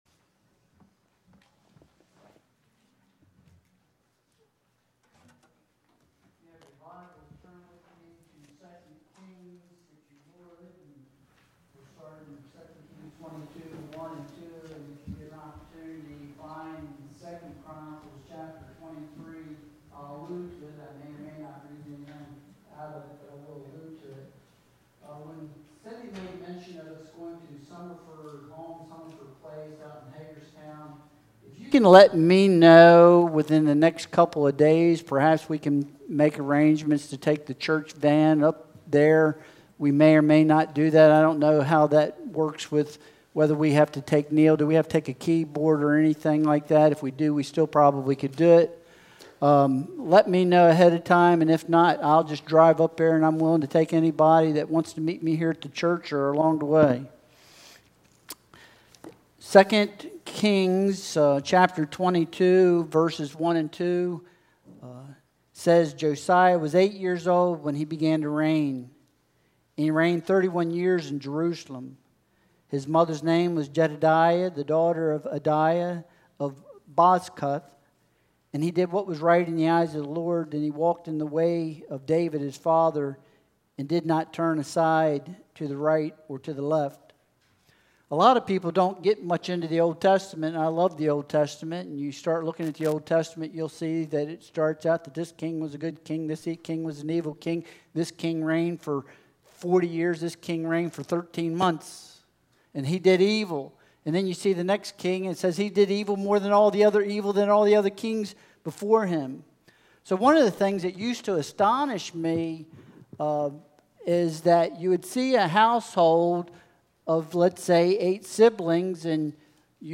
2 Kings 22:1-2 Service Type: Sunday Worship Service Download Files Bulletin « Guarding Your Heart